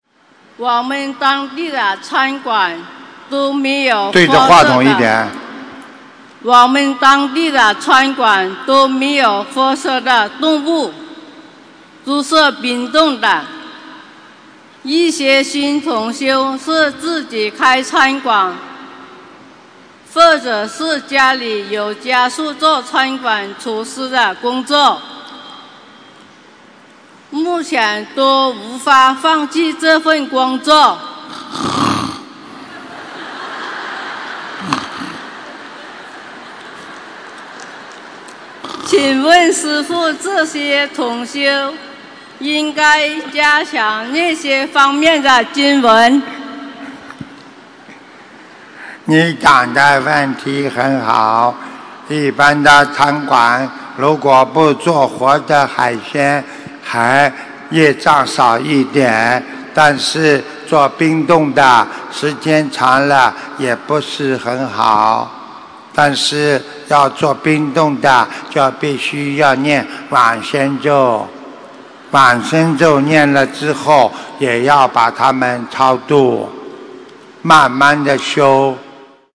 开餐馆但没有杀生，该加强哪些经文┃弟子提问 师父回答 - 2017 - 心如菩提 - Powered by Discuz!